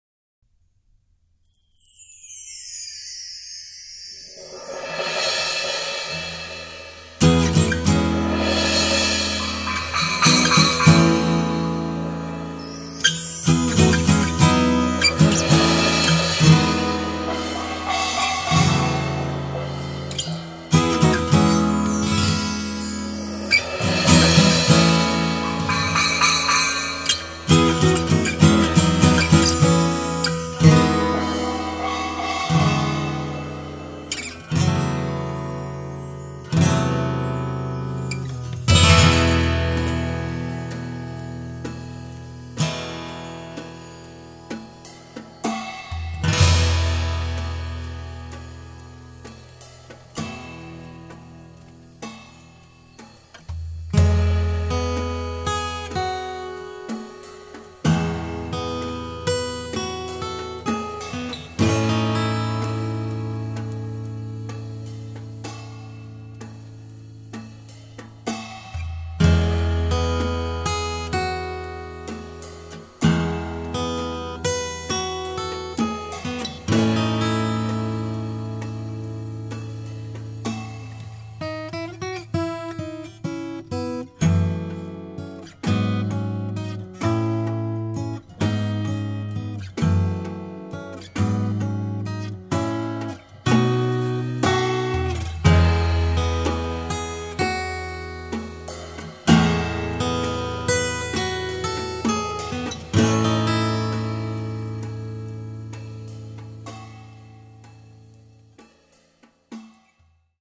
パーカッションとギターによる最小単位のオーケストラが奏でるのは
guitar